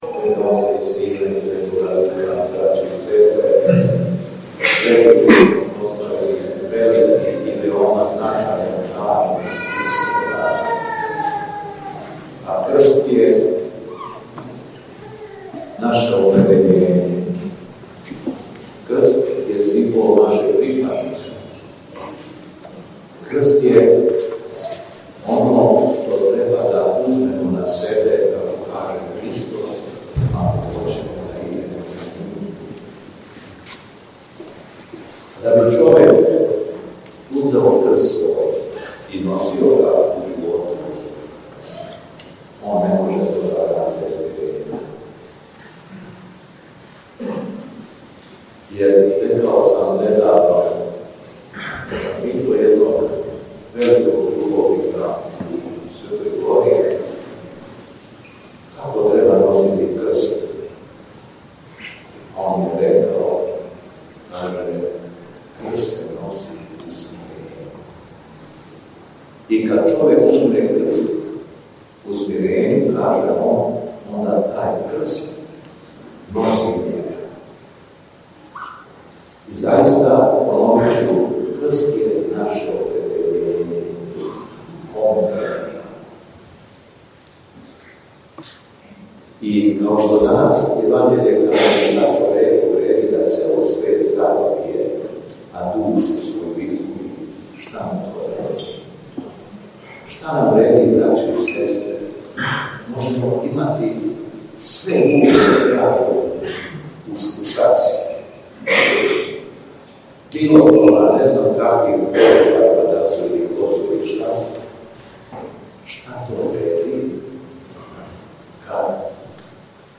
У недељу 29. септембра 2024. године када Црква прославља Свету великомученицу Јефимију, свету архијерејску Литургију, у Петки надомак Лазаревца, служио је Његово Високопреосвештенство Митрополит шумадијски Господин Јован.
Беседа Његовог Високопреосвештенства Митрополита шумадијског г. Јована